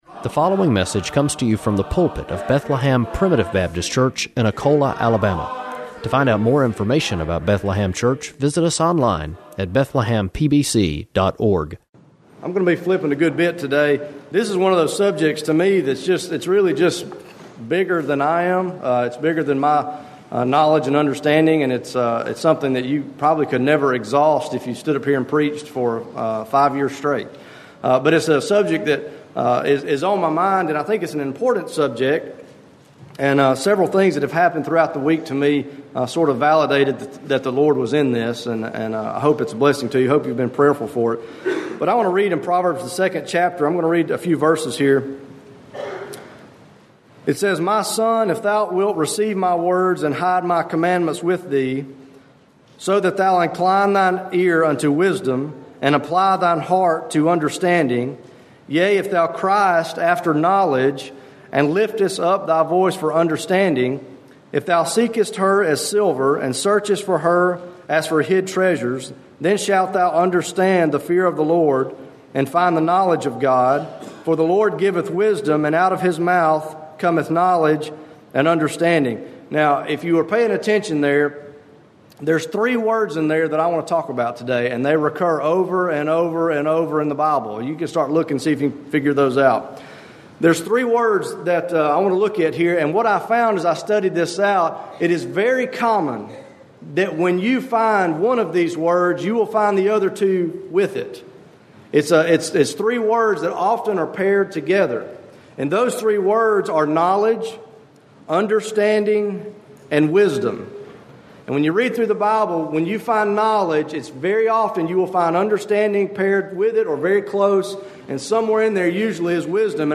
Preached July 8